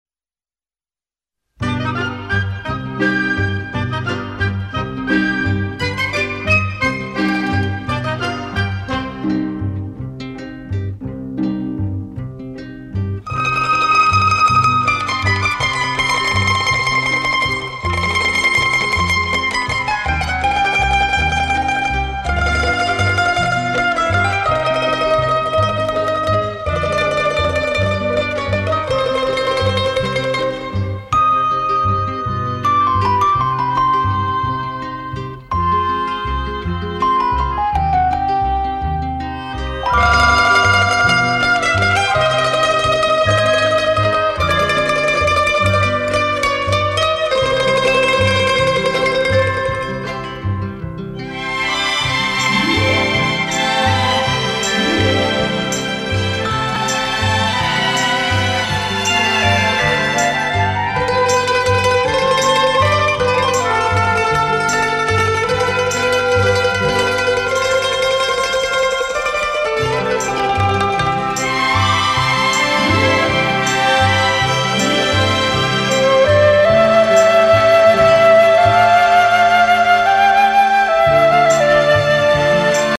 • Теги: минусовка